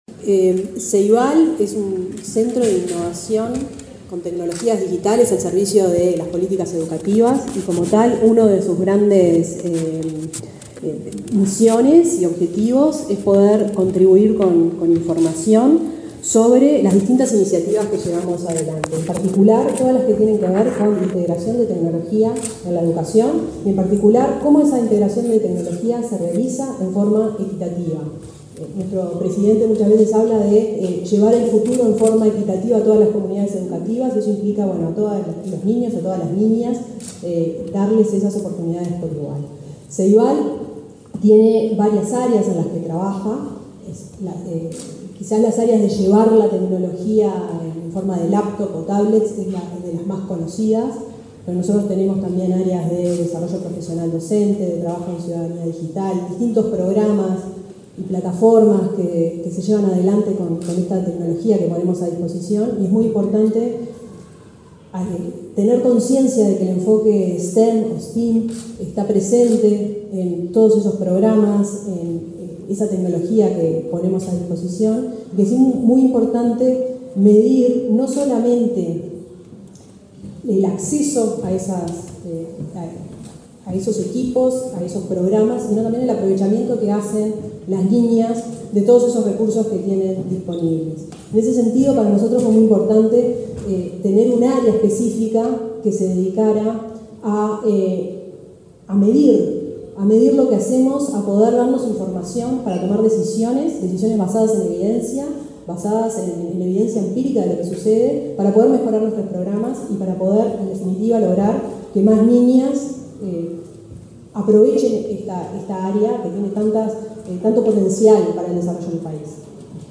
Palabra de autoridades en acto de Ceibal
La gerenta del Ceibal, Fiorella Haim, y la vicepresidenta Beatriz Argimón, participaron de la presentación del estudio Equidad de Género en el